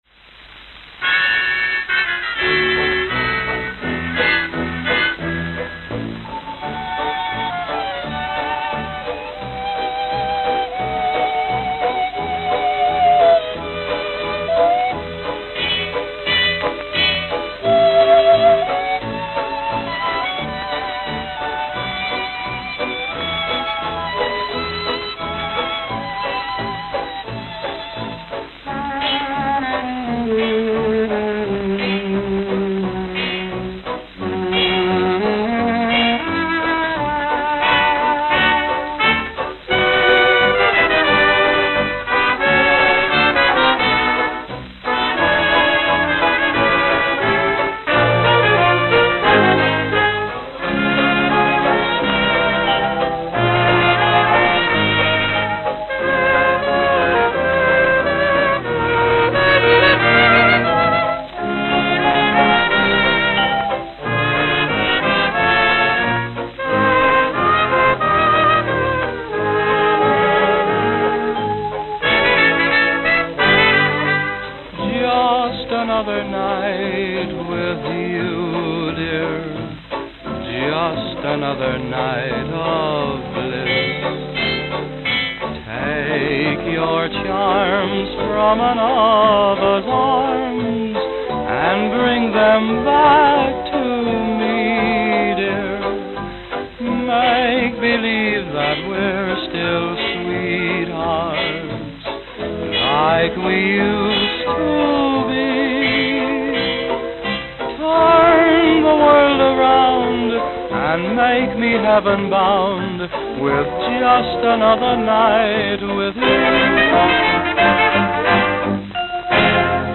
Chicago, Illinois Chicago, Illinois